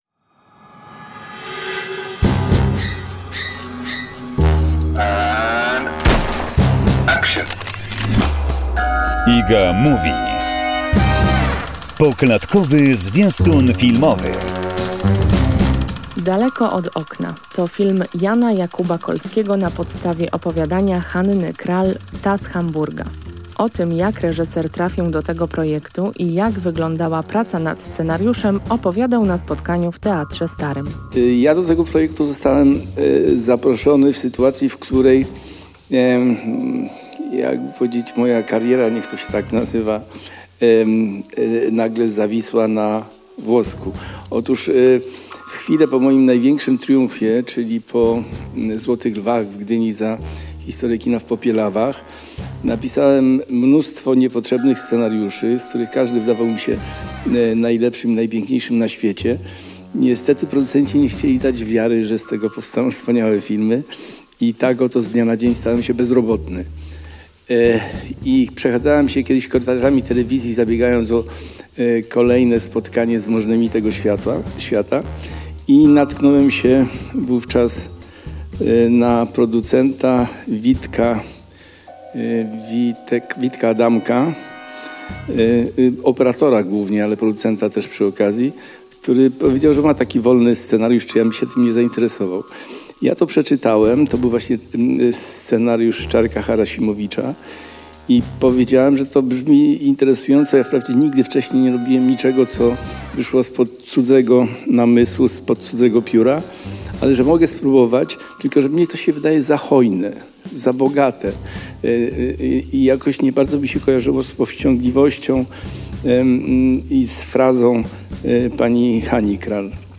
rozmowa z Janem Jakubem Kolskim